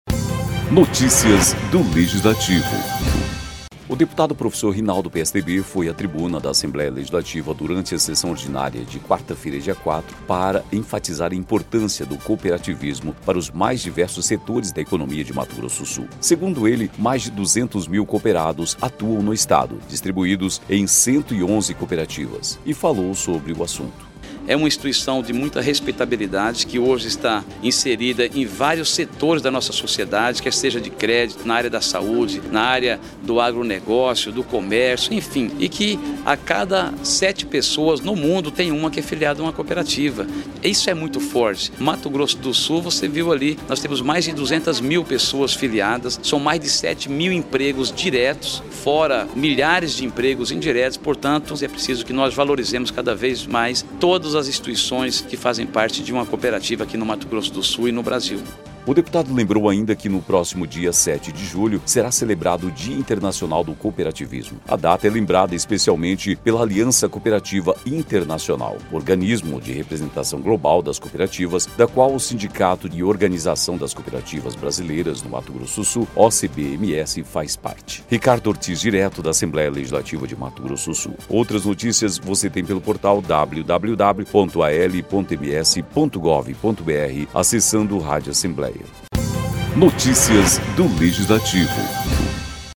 O deputado Professor Rinaldo (PSDB) foi à tribuna da Assembleia Legislativa, durante a sessão ordinária desta quarta-feira (4), para enfatizar a importância do cooperativismo para os mais diversos setores da economia de Mato Grosso do Sul.